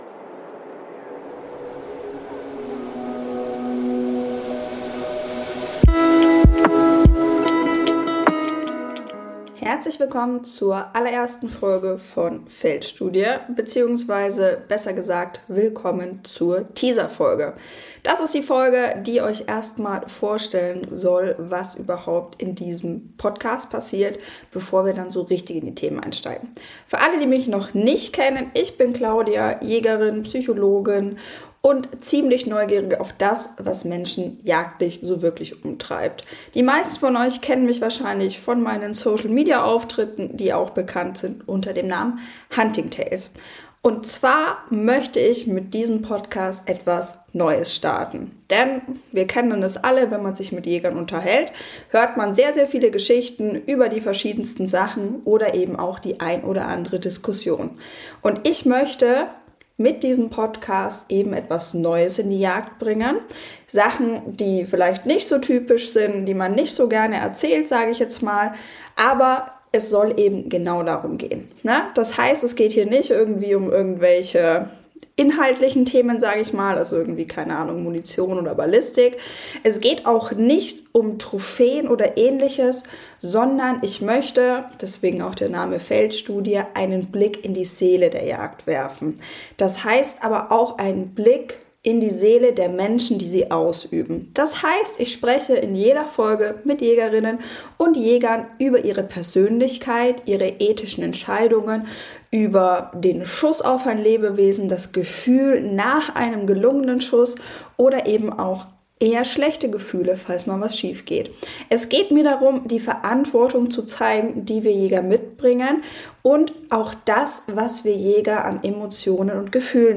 Transparent, ungeschnitten und authentisch.